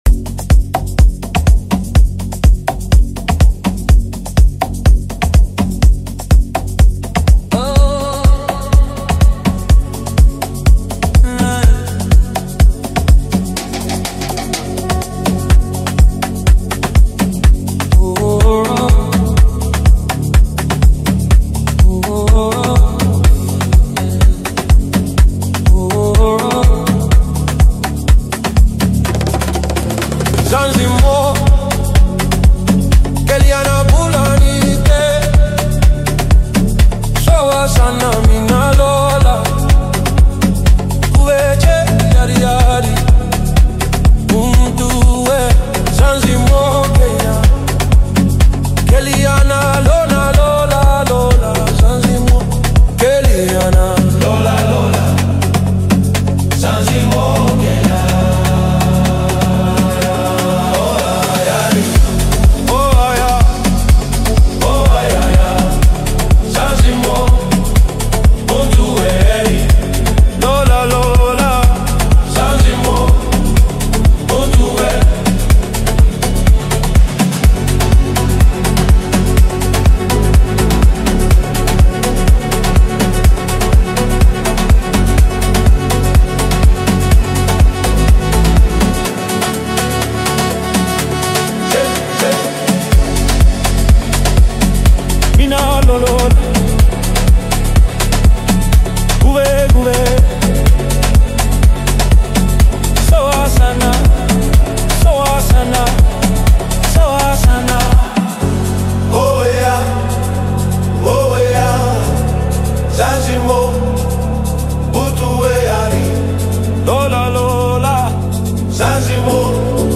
Award winning singer-songwriter